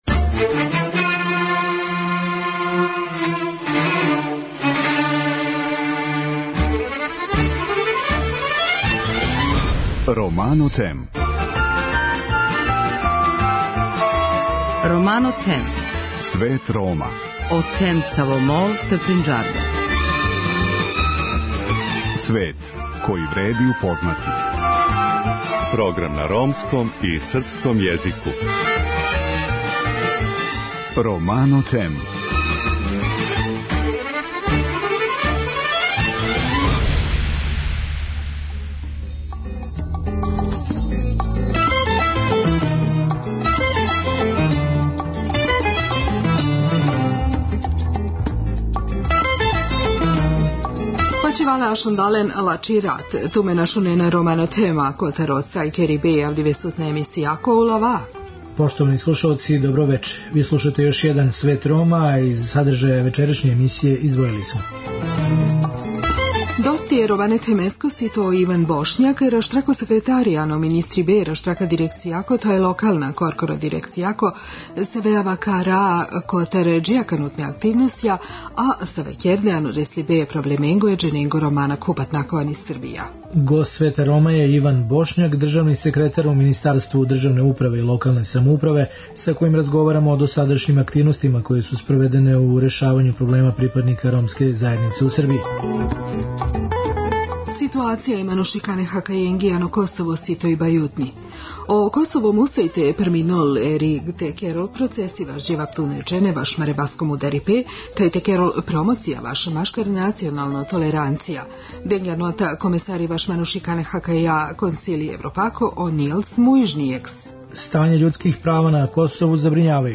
Гост Света Рома је Иван Бошњак, државни секретар у Министарству државне управе и локалне самоуправе, са којим разговарамо о досадашњим активностима које су спроведене у решавању проблема припадника ромске заједнице у Србији.